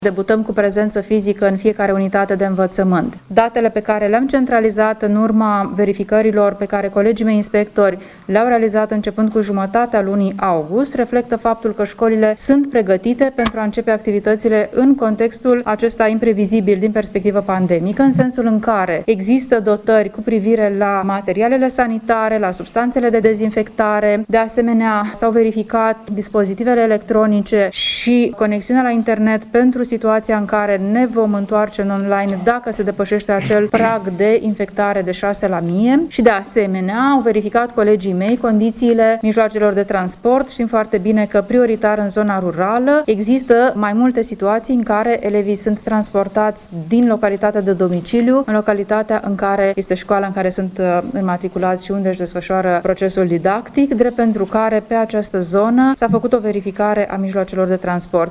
În judeţul Iaşi, circa 130.000 de elevi şi preşcolari sunt aşteptaţi, mâine, să înceapă noul an de învăţământ, a anunţat, la o conferinţă de presă, inspectorul şcolar general, Luciana Antoci.